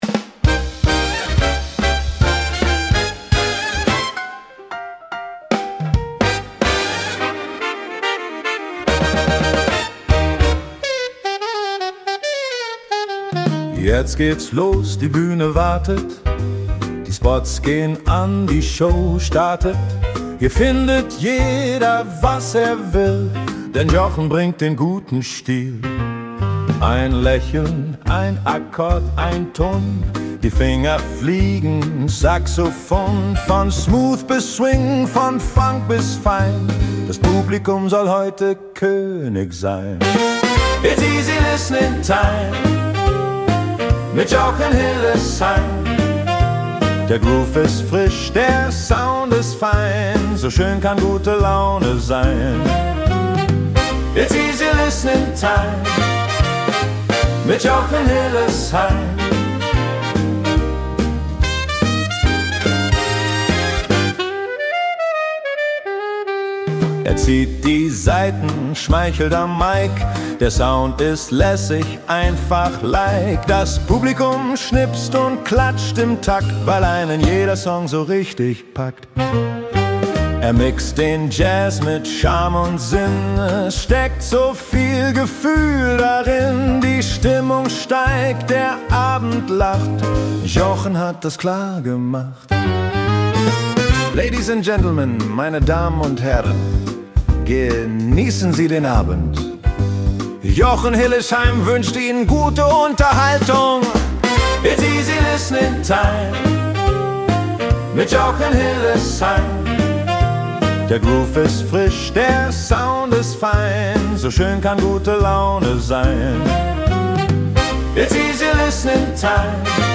Nach Ihrem Briefing und Input erstelle ich Konzept, Text und Sounddesing für Ihren Song, die Einspielung erfolgt dann durch KI-Softwaretools.